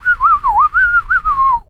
bird_sparrow_tweet_04.wav